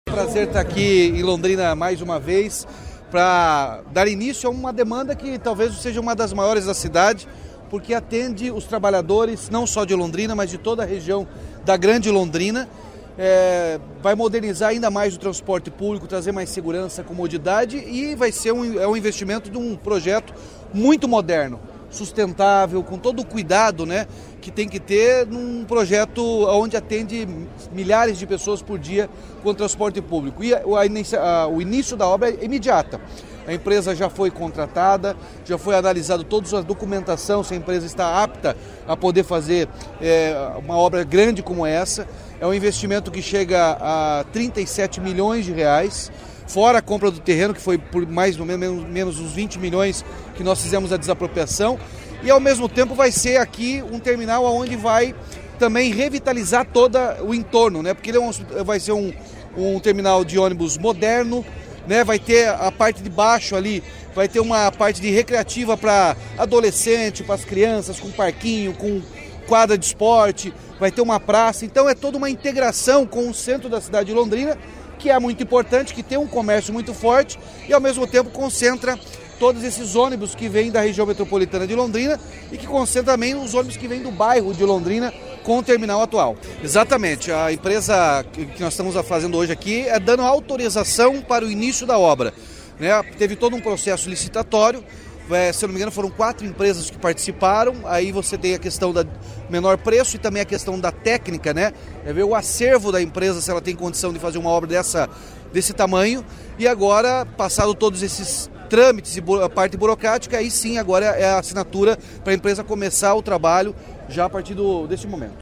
Sonora do governador Ratinho Junior sobre o novo Terminal Metropolitano de Londrina